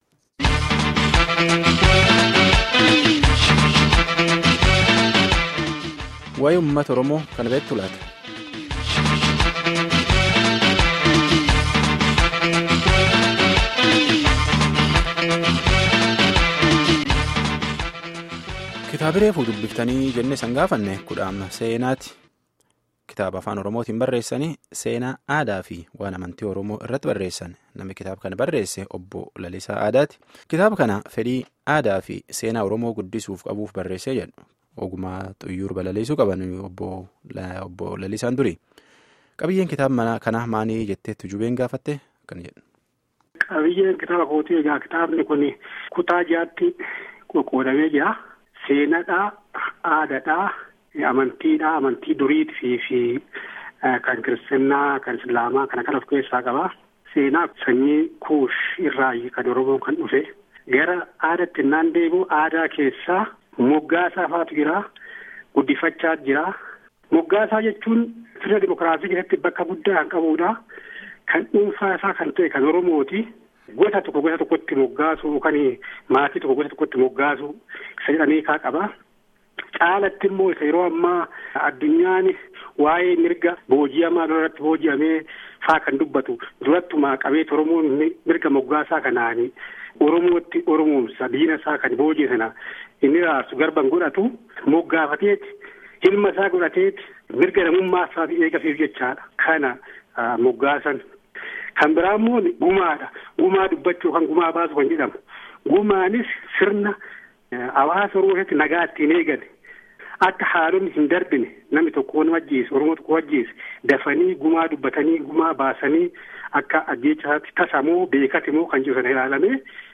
Gaaffii fi deebii guutuu armaa gadiitti dhaggefadhaa